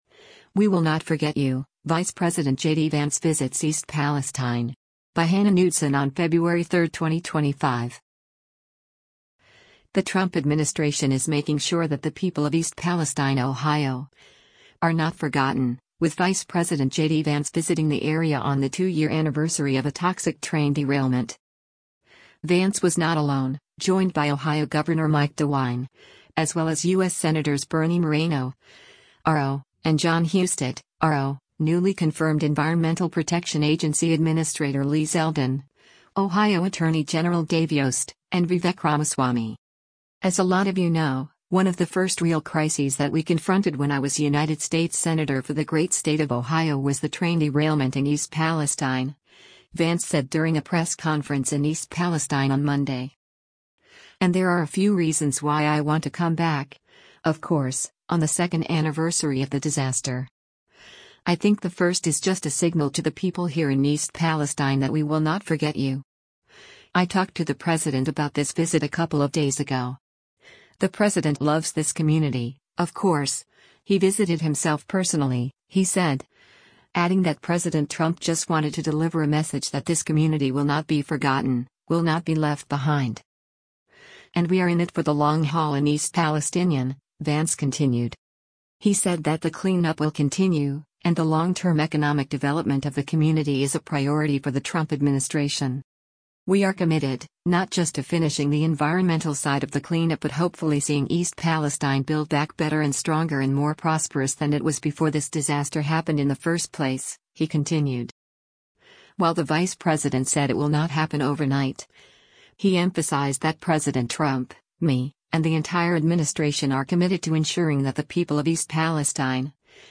“As a lot of you know, one of the first real crises that we confronted when I was United States senator for the great state of Ohio was the train derailment in East Palestine,” Vance said during a press conference in East Palestine on Monday.